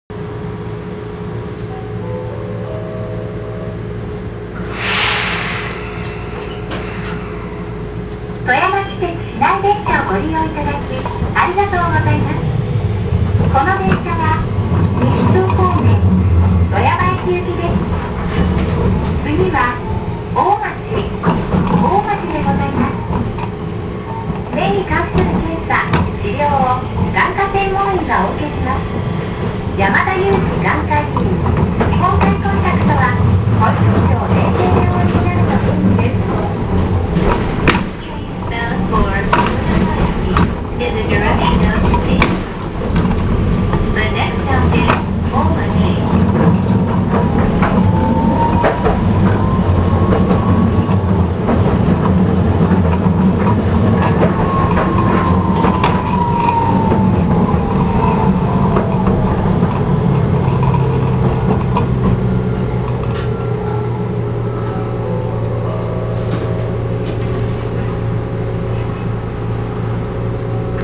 ・T100形走行音
【市内軌道線】南富山駅前〜大町（1分10秒：386KB）